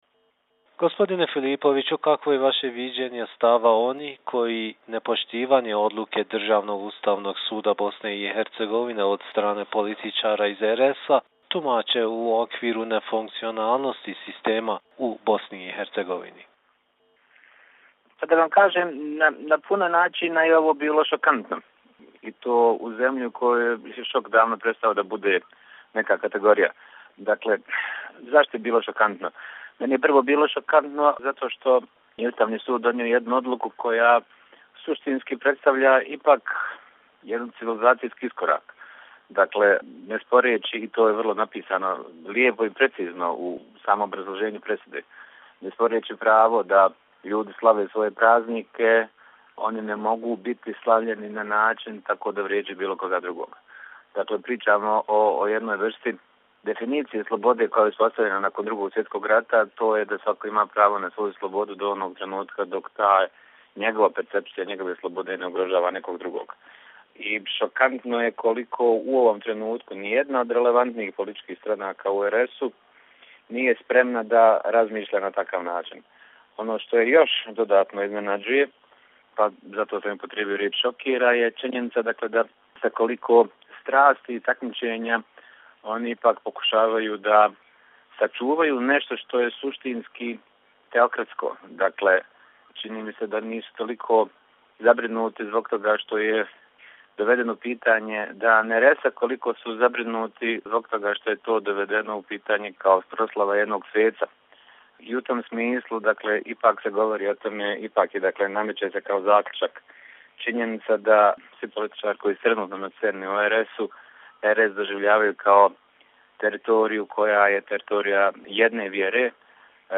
Tonske izjave